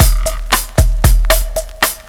Bossa Back 02.WAV